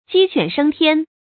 注音：ㄐㄧ ㄑㄨㄢˇ ㄕㄥ ㄊㄧㄢ
雞犬升天的讀法